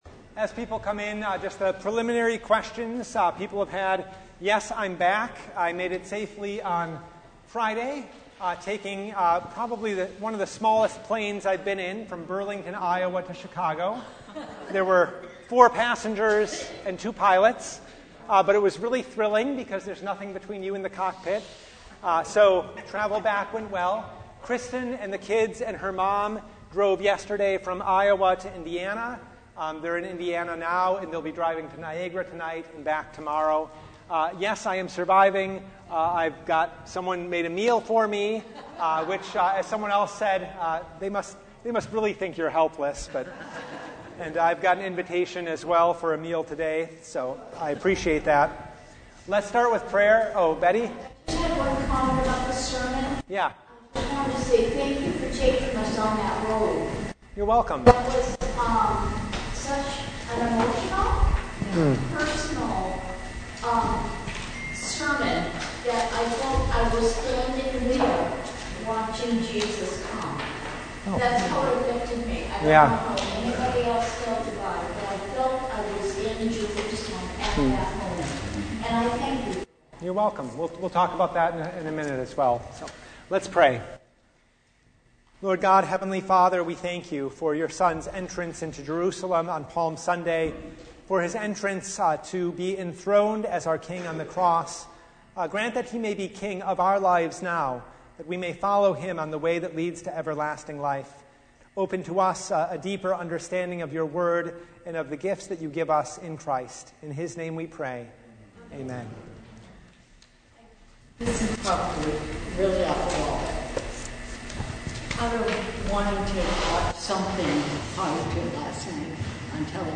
Service Type: Bible Hour